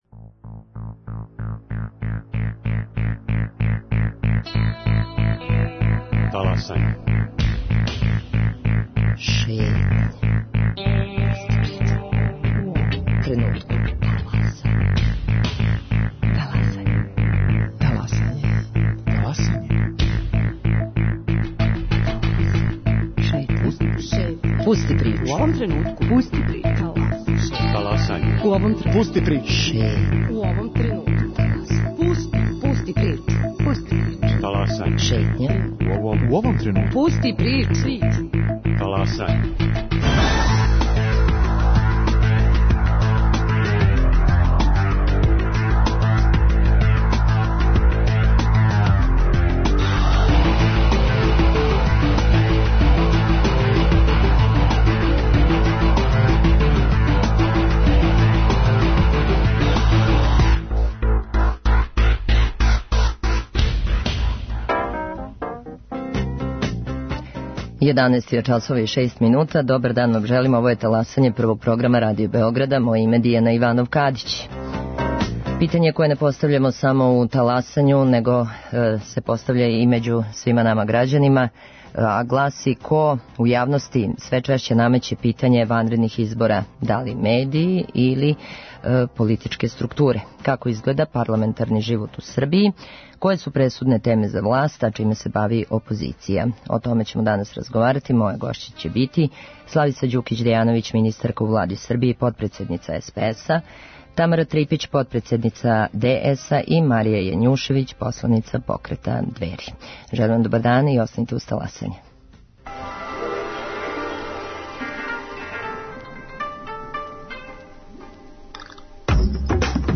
За Таласање говоре: Славица Ђукић Дејановић, министарка у Влади Србије и потпредседница СПС-а;Тамара Трипић, потпредседница ДС-а и Марија Јањушевић, посланица покрета Двери.